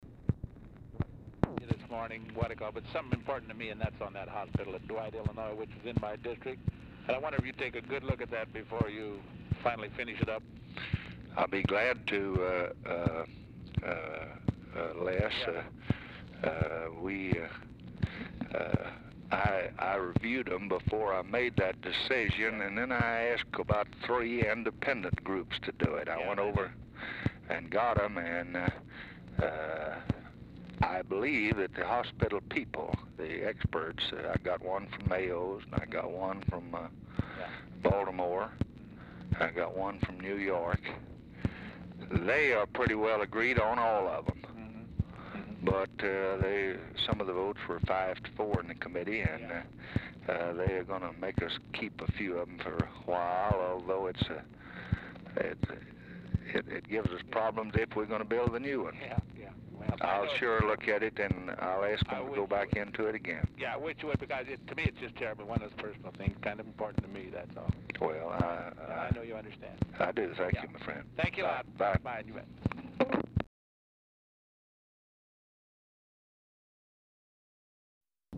Telephone conversation # 7637, sound recording, LBJ and LESLIE ARENDS, 5/12/1965, 12:00PM | Discover LBJ
RECORDING STARTS AFTER CONVERSATION HAS BEGUN
Format Dictation belt